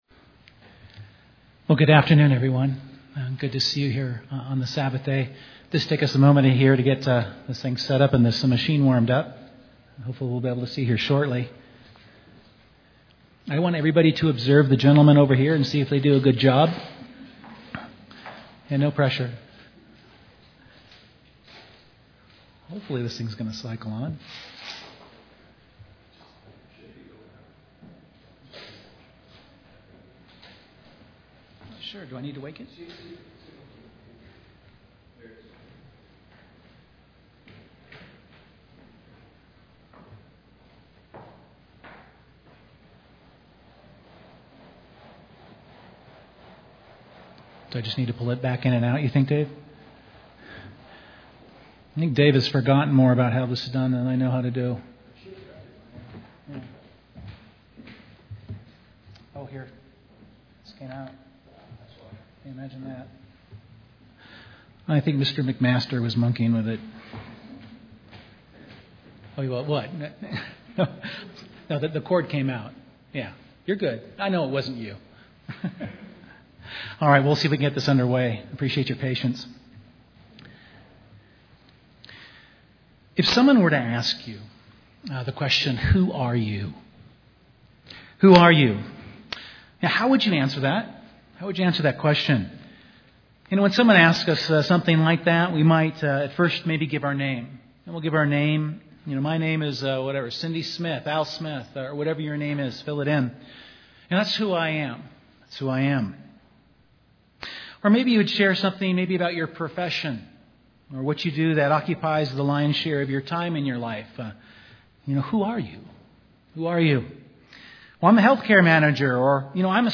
The bible uses salt to symbolize many things. This sermon focuses on the symbolism of salt with a particular focus on what Jesus Christ taught about salt and what it means to be the “salt of the earth” (Matthew 5:13).